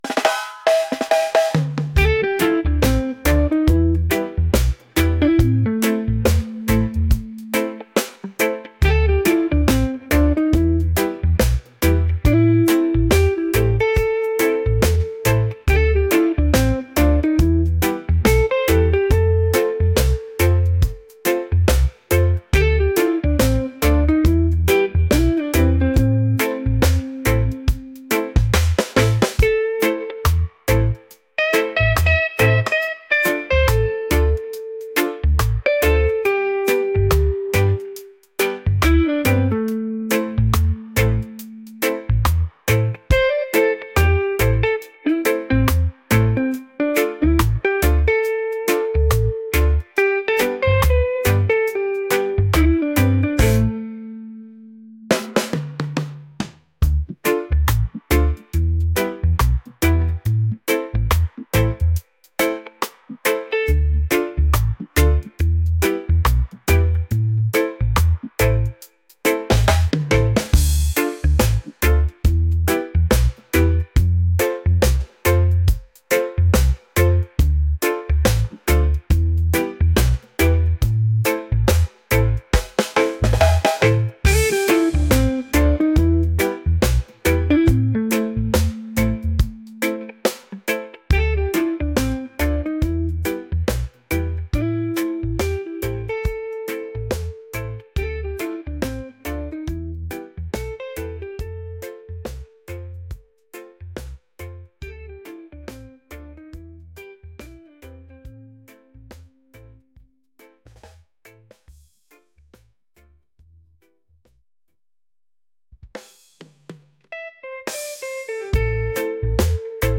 laid-back | soulful | reggae